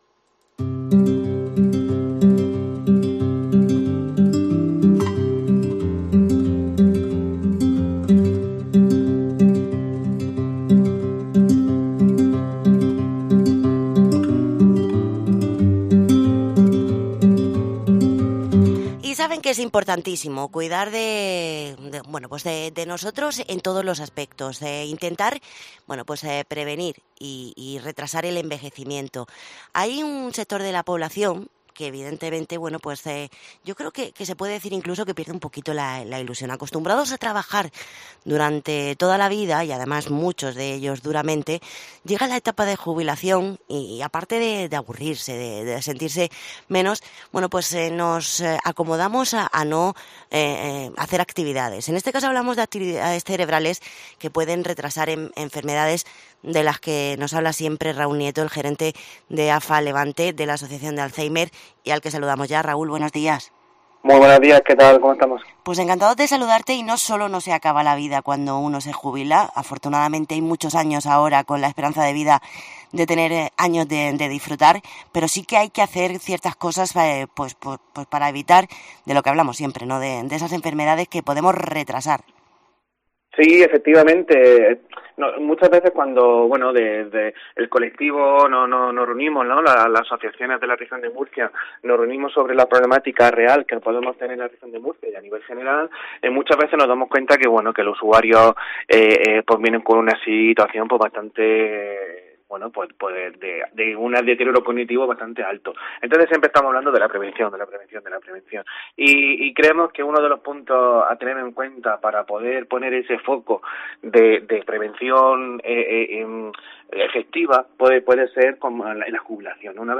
Entrevista AFA Levante